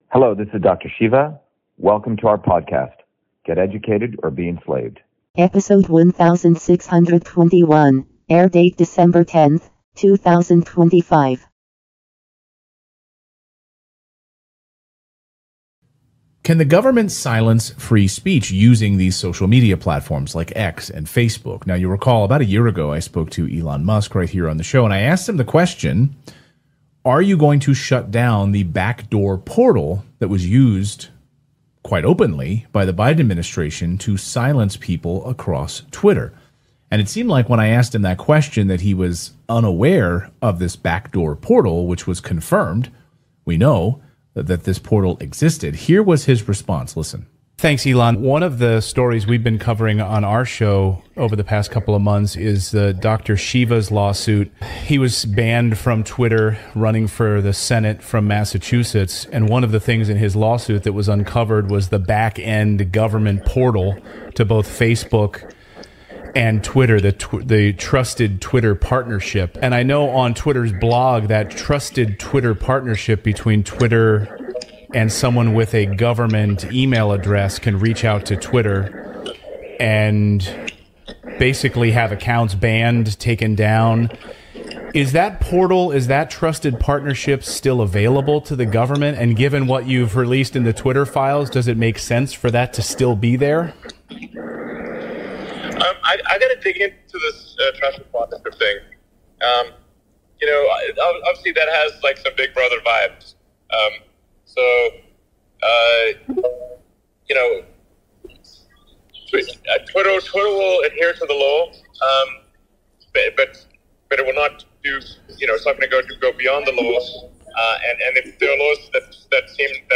In this interview, Dr.SHIVA Ayyadurai, MIT PhD, Inventor of Email, Scientist, Engineer and Candidate for President, Talks about The TRUTH About Twitter’s Backdoor Portal, Elon and the Battle for Free Speech